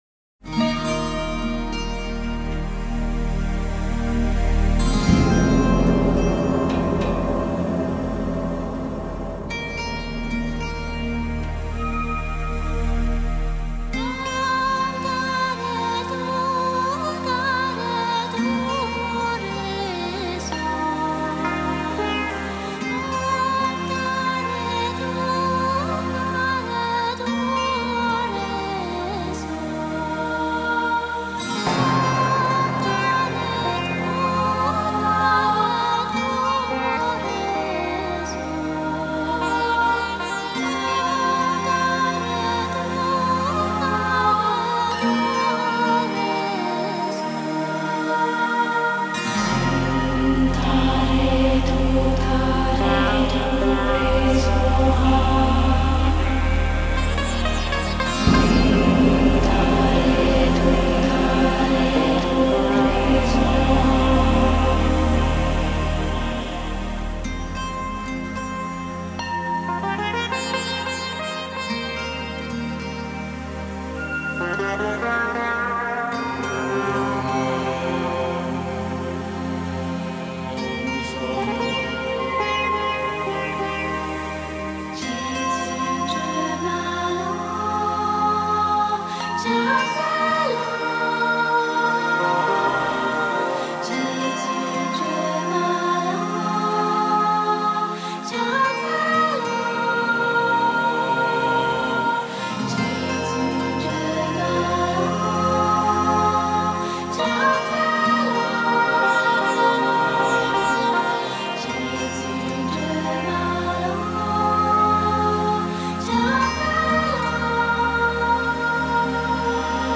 The music is Himalayan meditation tracks. Very relaxing….
475 tara_mantra.mp3